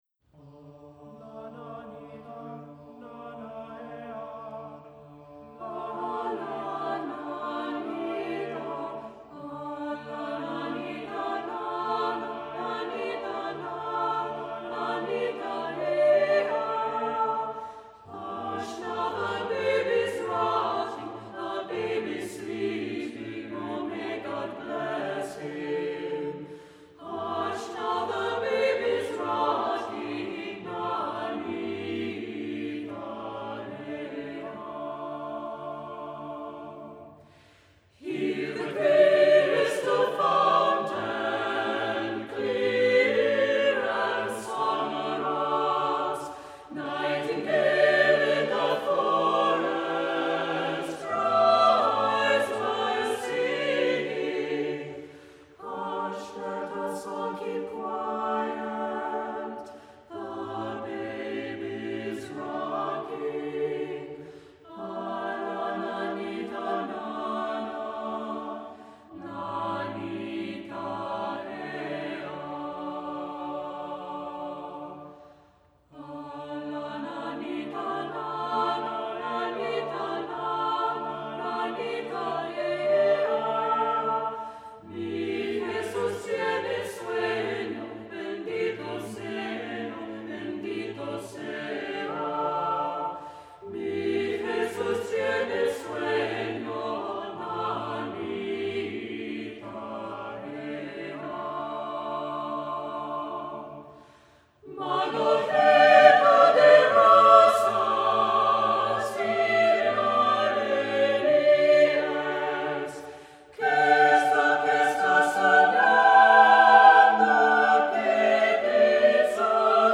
Accompaniment:      Reduction
Music Category:      Choral